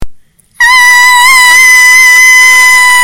Sound Effects
Screaming Girl